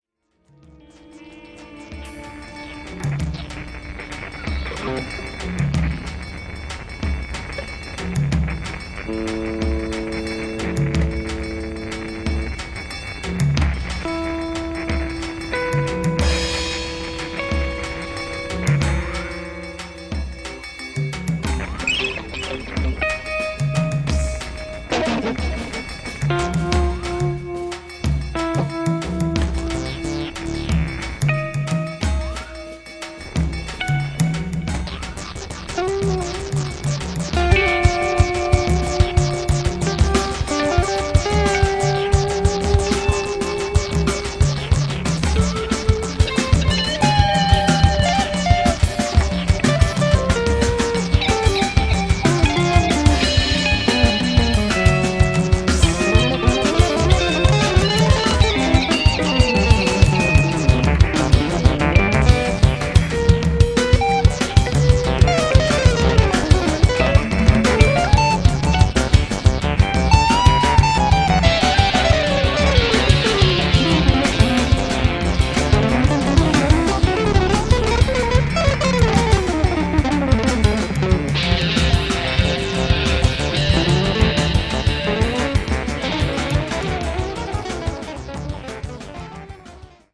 In short, it grooves as it moves.